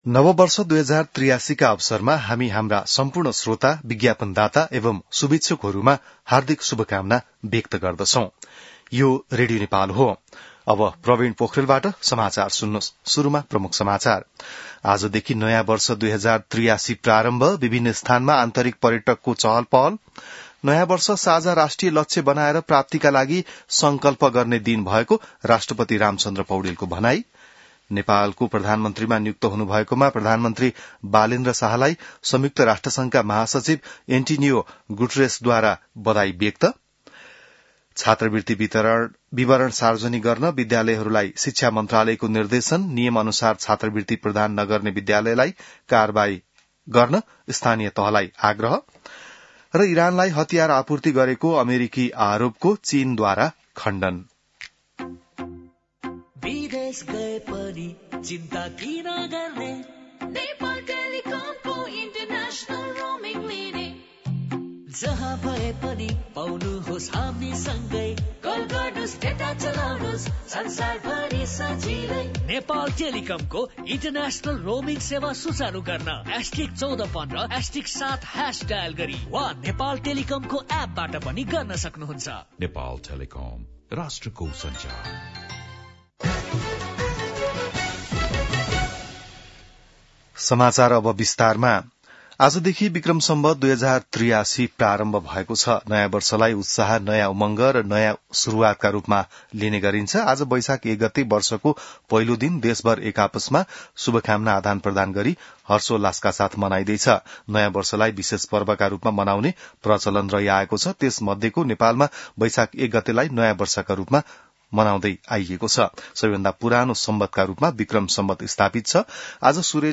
बिहान ७ बजेको नेपाली समाचार : १ वैशाख , २०८३